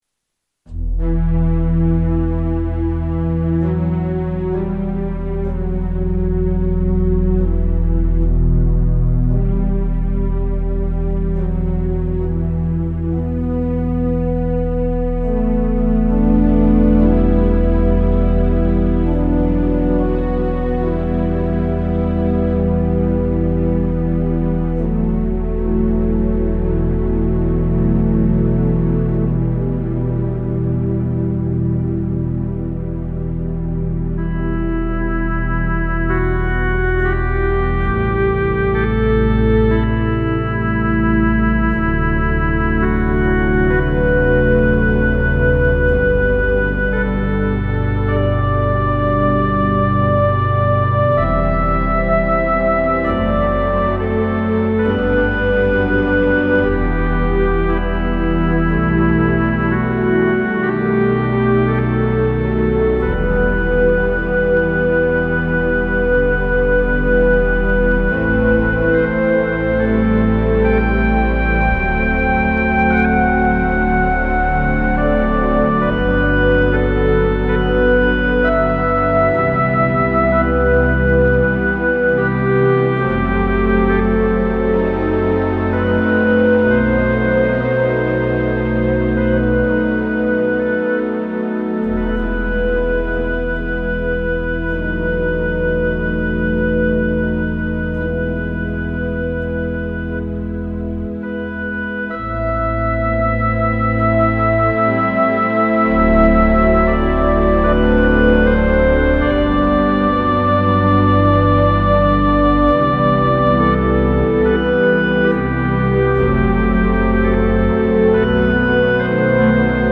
All of the tracks that follow are (mostly) piano improvisations, each performed with only one pass and having no edits or other alterations following the performances.
28 February 2026 (organ solo) (Later transcribed as Meditation 284)